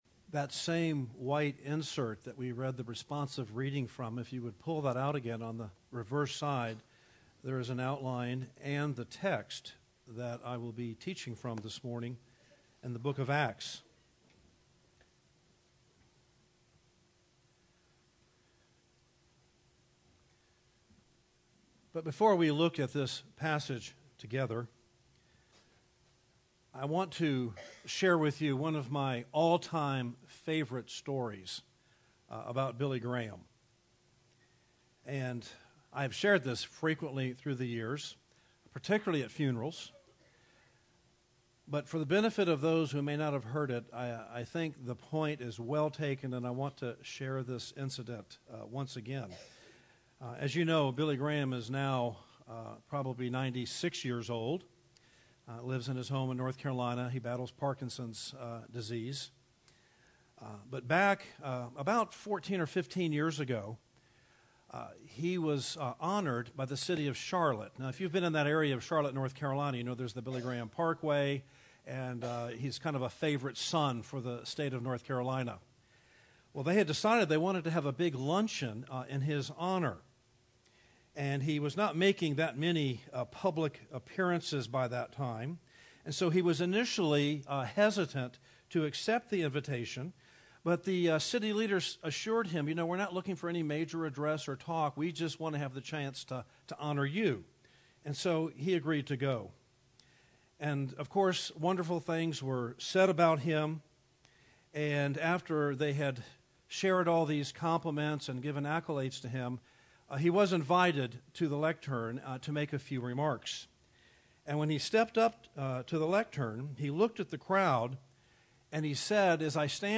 Death Overpowered » The Chapel Church of Gainesville, Florida